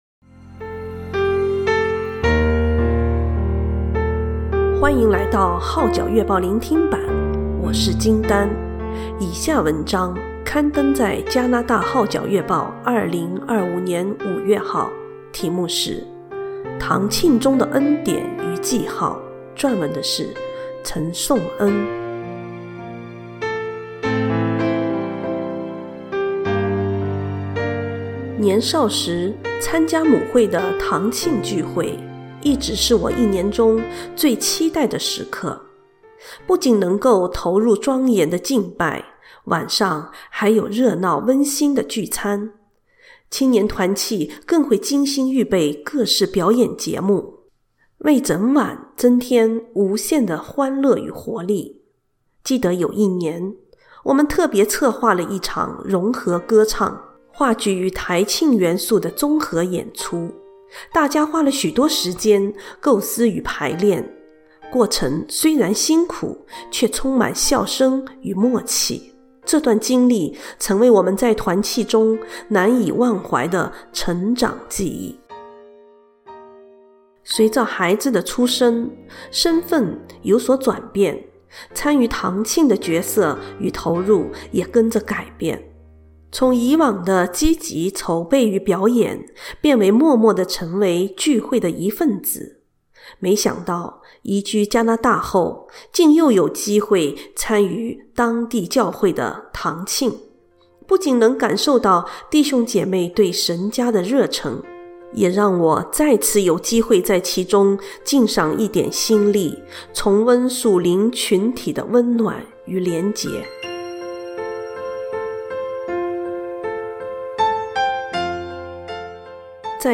聆聽版/Audio堂慶中的恩典與記號